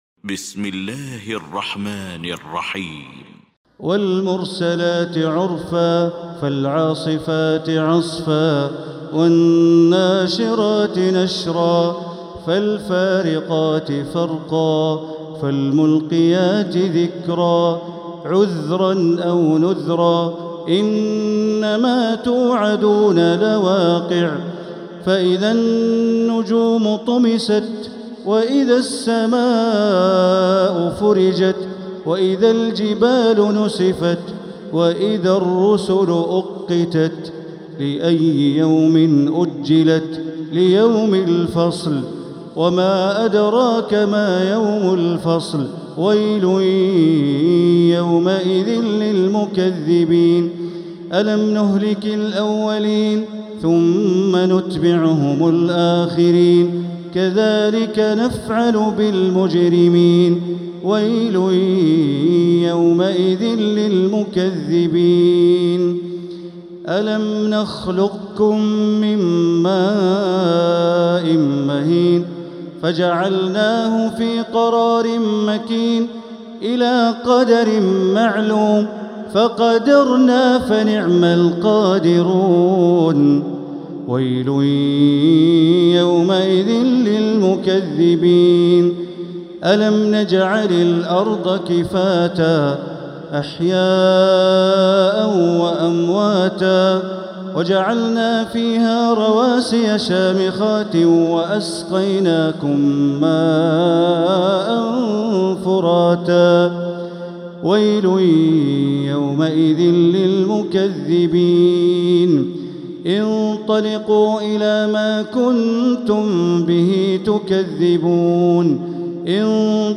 المكان: المسجد الحرام الشيخ: معالي الشيخ أ.د. بندر بليلة معالي الشيخ أ.د. بندر بليلة المرسلات